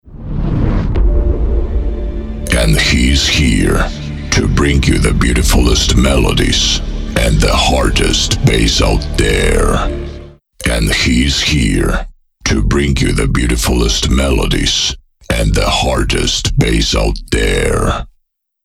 Choose your favourite a ready intro! 60 professional templates - Opener DJ INTRO!
Vocal from the selected intro in acapella format (without music) (DRY VOICE ONLY)
FULL-ACAPELLA.mp3